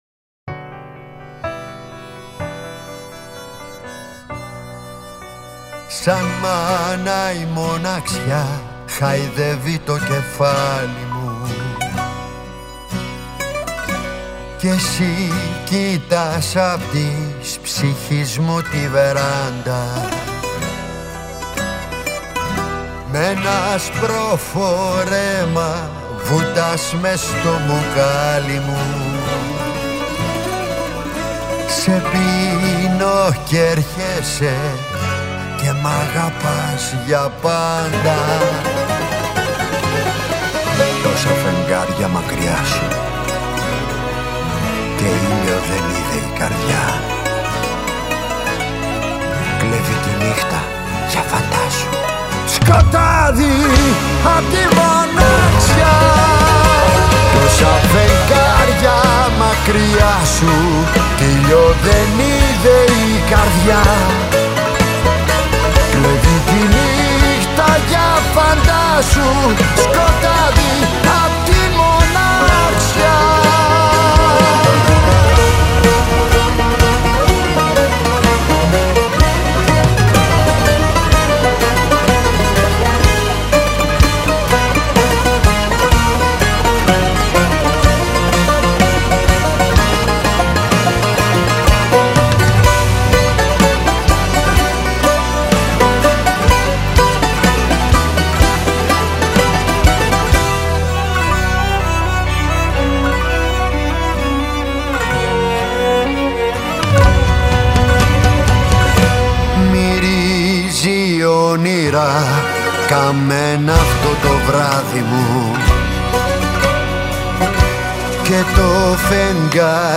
Όταν ο πόνος σε 9 όγδοα συνάντησε την αίσθηση των 4 τετάρτων
Rock άποψη της σύγχρονης μουσικής.
Μια διαπεραστική ανατριχίλα σε σώμα, πνεύμα και ψυχή.